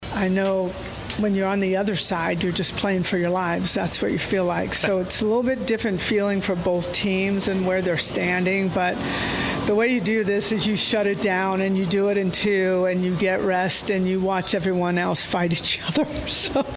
Head coach Patty Gasso talked about finishing off the supers in sweeping fashion.